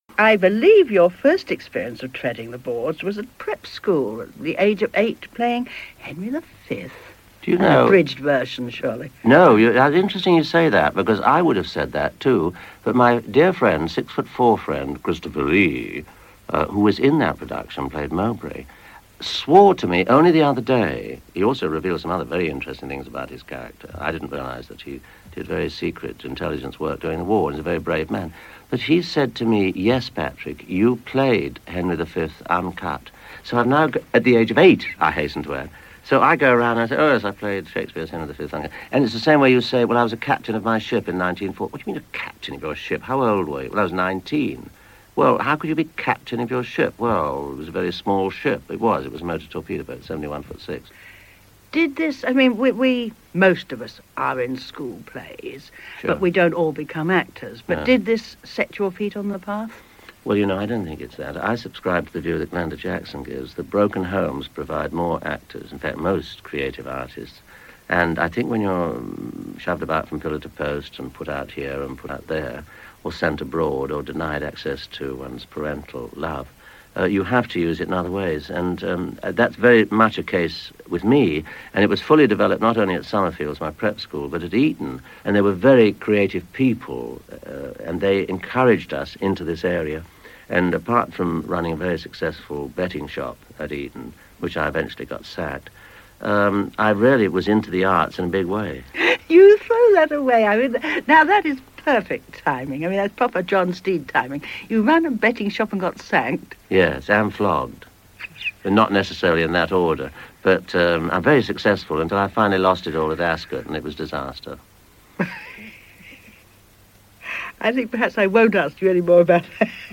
In this edited version he's typically charming and self deprecating.
This interview comes from an edition of BBC Radio 2's long running cinema series Start Sound Extra.